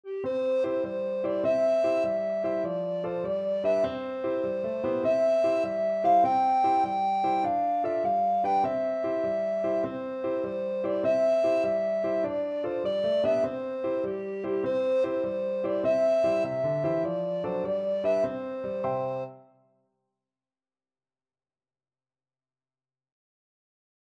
Alto Recorder
Quick two in a bar . = c.100
C major (Sounding Pitch) (View more C major Music for Alto Recorder )
6/8 (View more 6/8 Music)
Traditional (View more Traditional Alto Recorder Music)